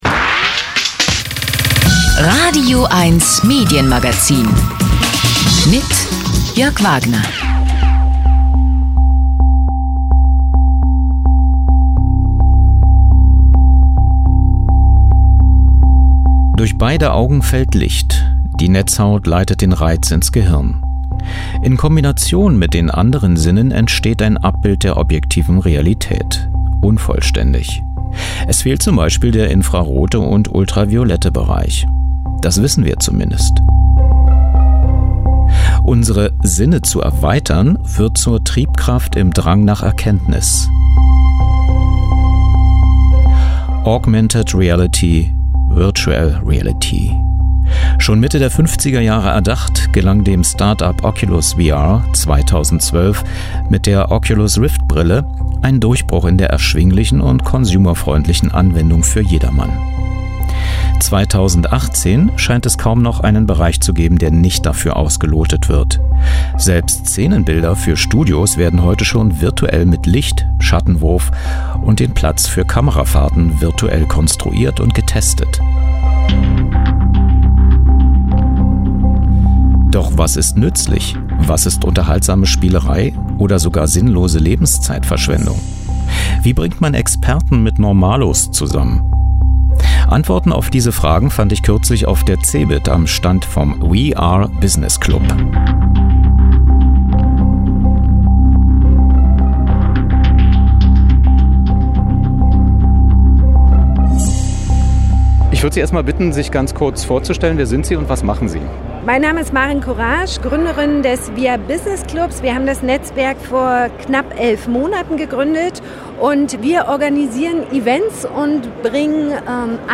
Interview über AR, VR, MR
CEBIT, Hannover